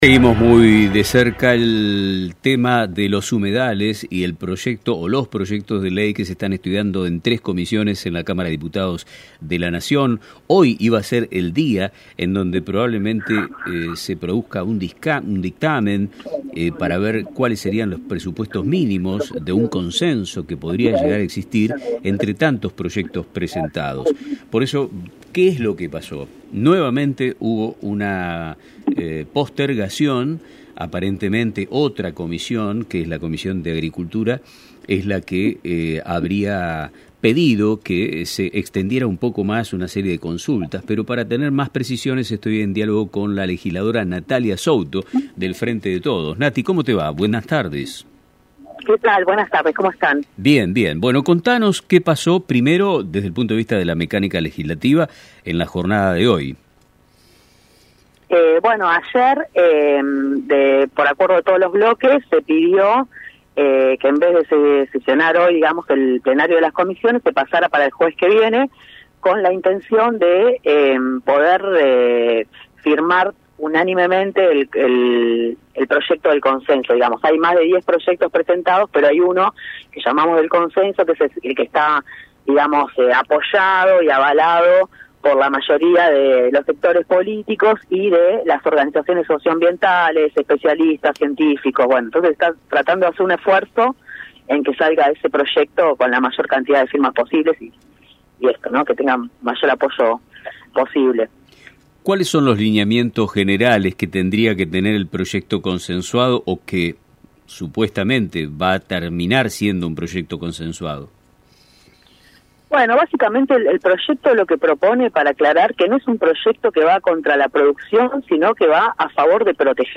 A continuación, escuchamos la palabra de la legisladora en diálogo con «Nuestro Tiempo de Radio»: